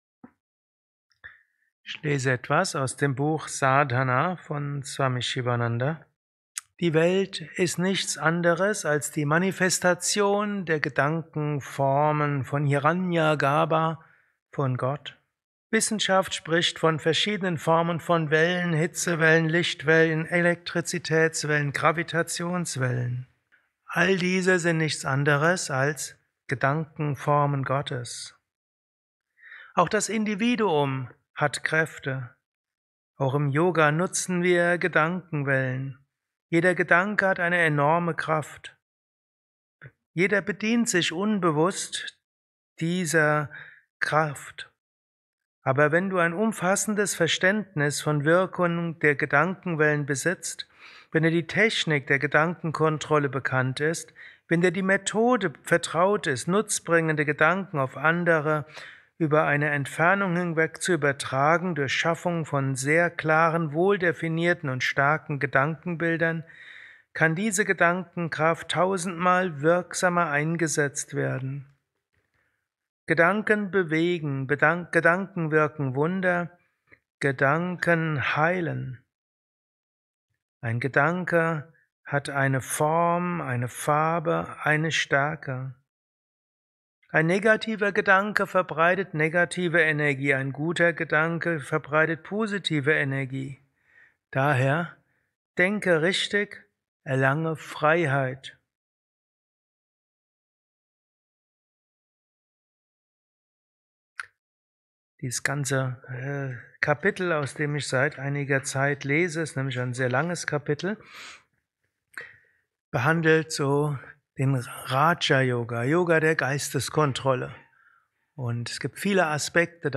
Kurzvorträge
Satsangs gehalten nach einer Meditation im Yoga Vidya Ashram Bad